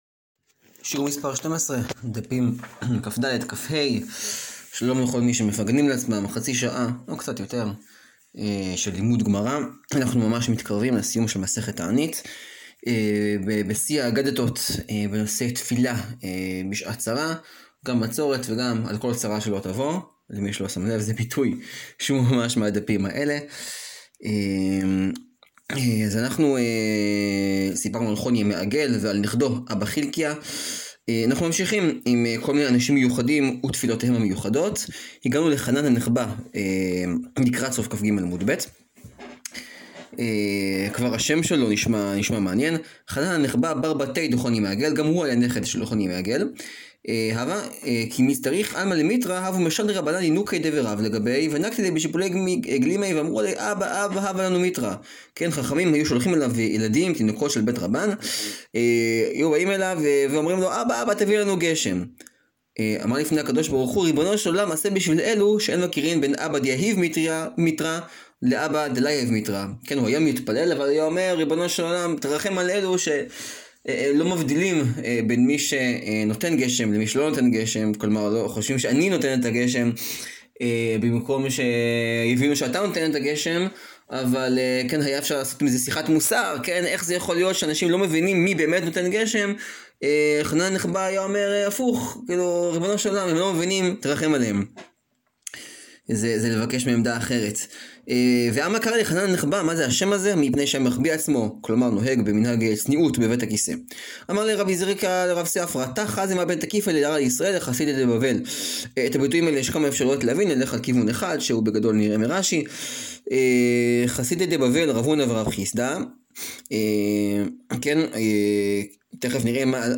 סיום מסכת תענית – שיעור 12 - האותיות הקטנות